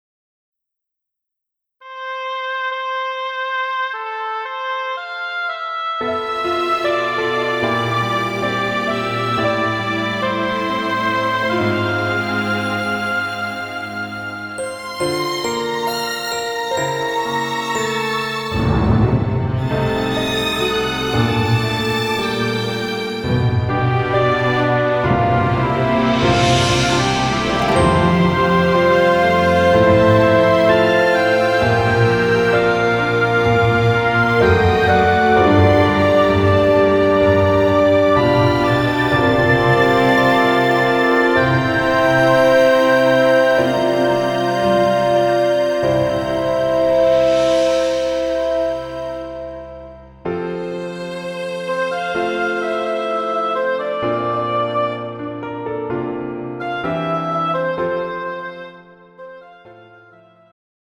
음정 여자-1키
장르 축가 구분 Pro MR